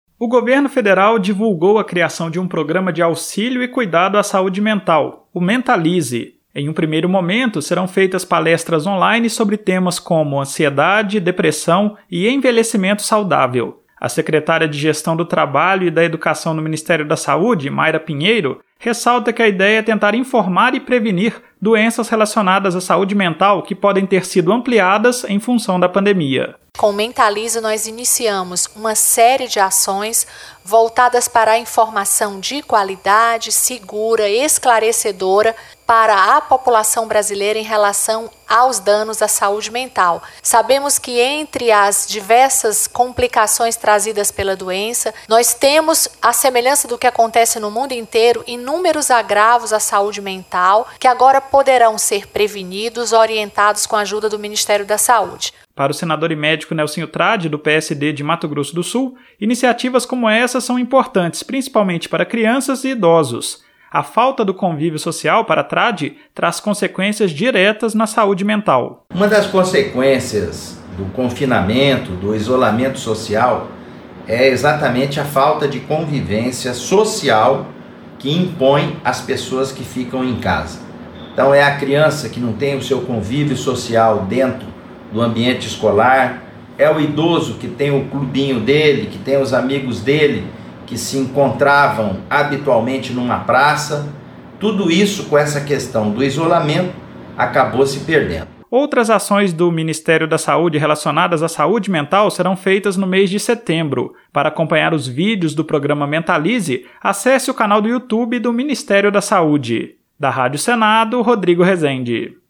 O senador Nelsinho Trad (PSD-MS), que é médico, afirmou que programas com esse são importantes principalmente neste momento de pandemia.